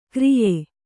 ♪ kriye